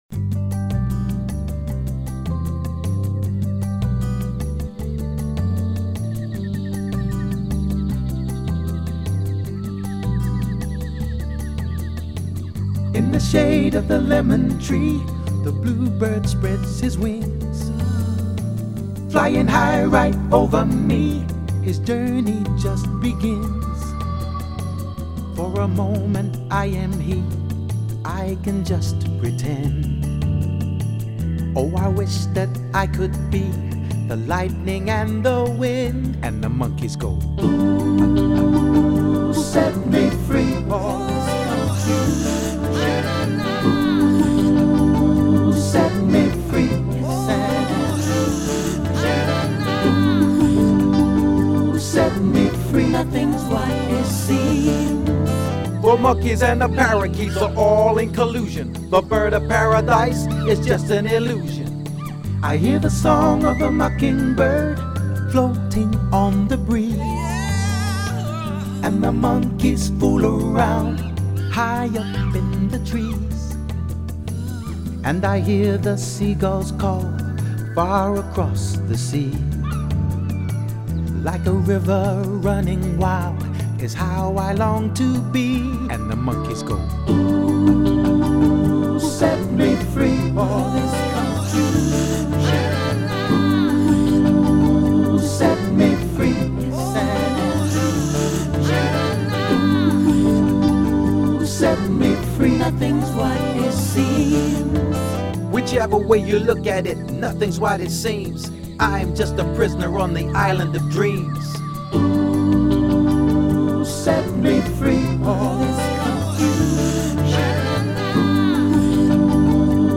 full vocal